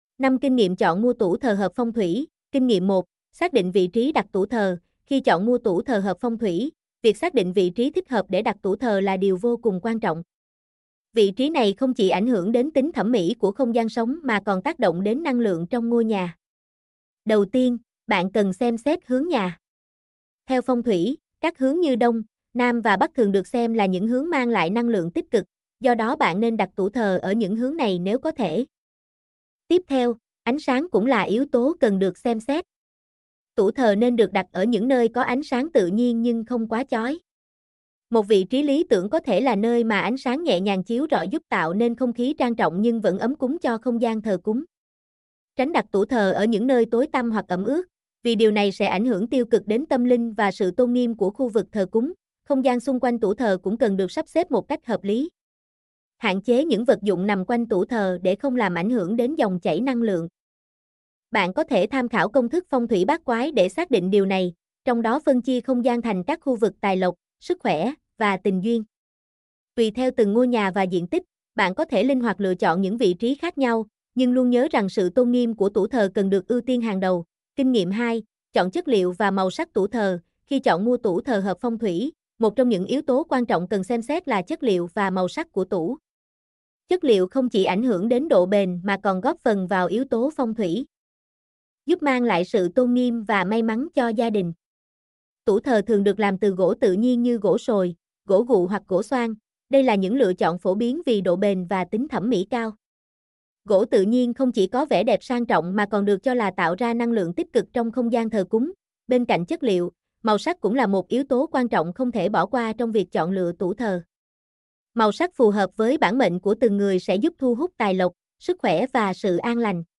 mp3-output-ttsfreedotcom-26.mp3